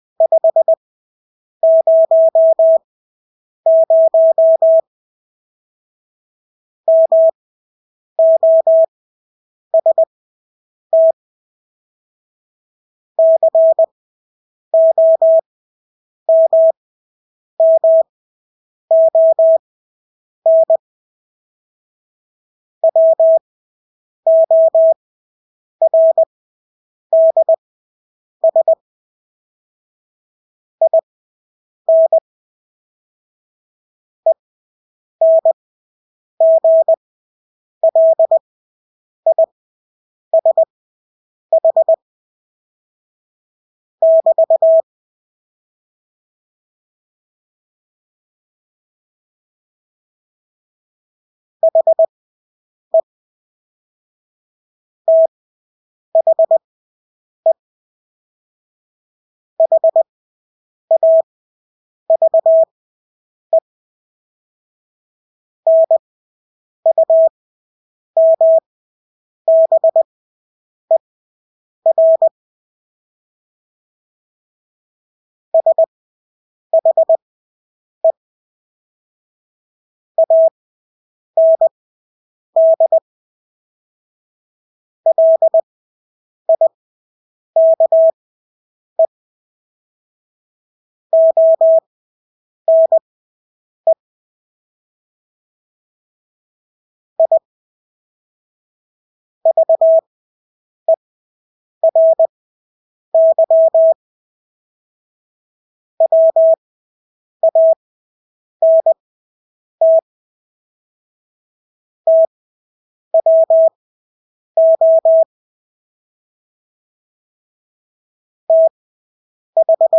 Engelske ord 20/8 wpm | CW med Gnister
Engelske ord 20-8 wpm.mp3